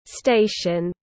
Station /ˈsteɪ.ʃən/